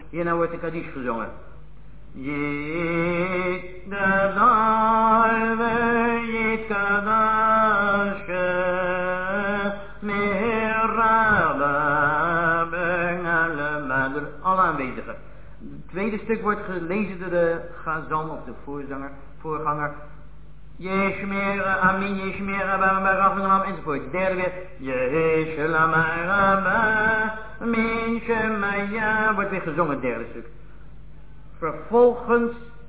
Festive (a.o. used during Limud)